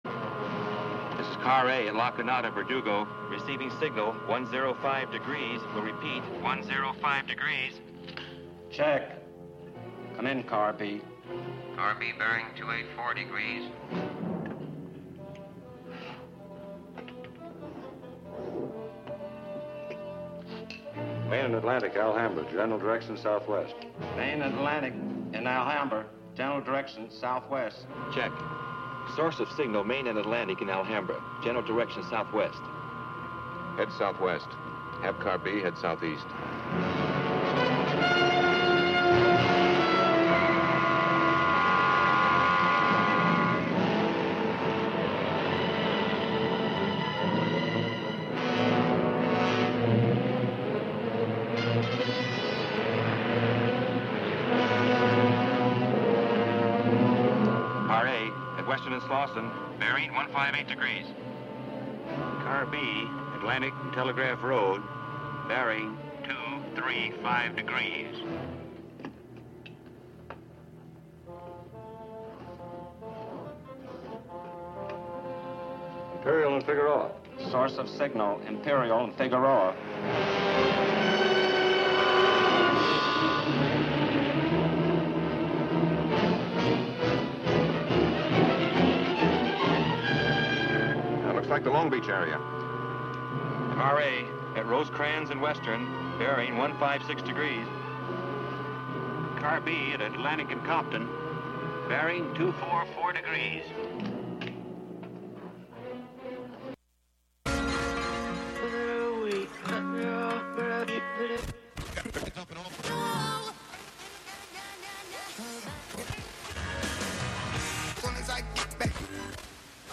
11am Live from Brooklyn, New York
making instant techno 90% of the time